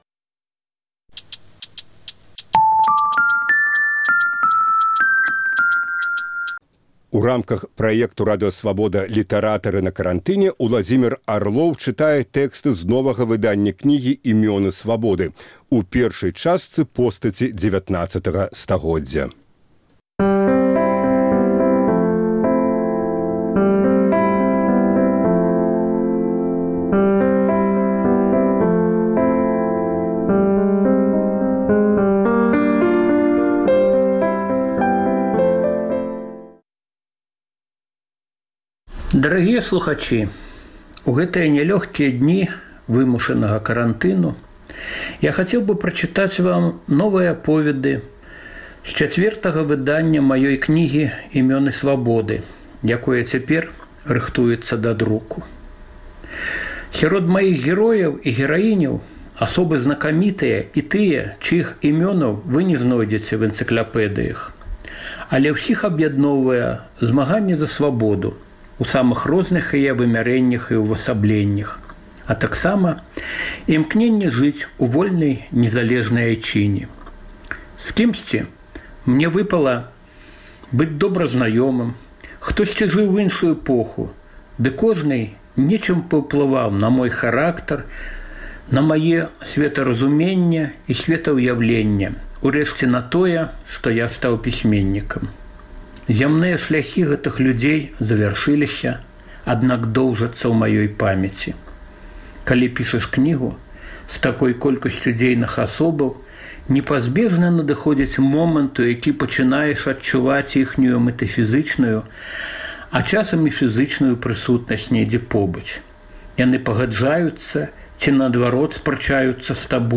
У рамках праекту Радыё Свабода “Літаратары на карантыне” Ўладзімер Арлоў чытае тэксты з новага выданьня кнігі “Імёны Свабоды”. У першай частцы - постаці ХІХ стагодзьдзя.